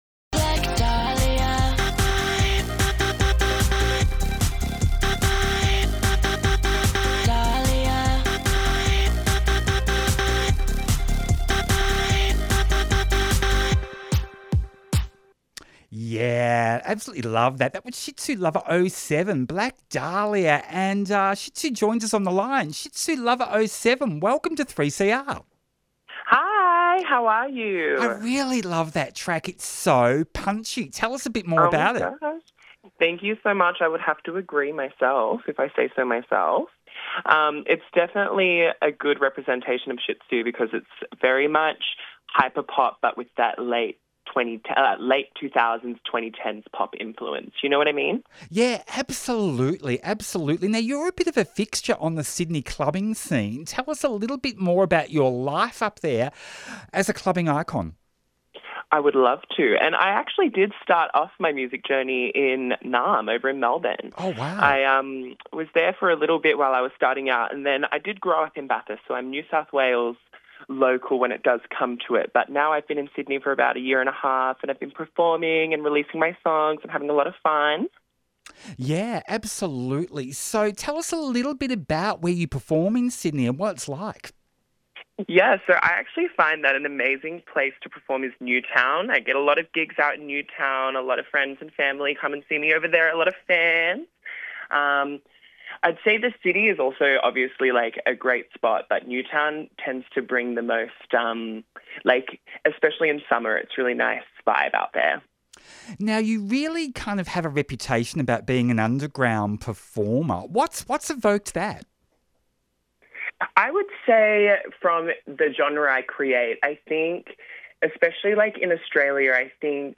Interview starts 18:27 minutes.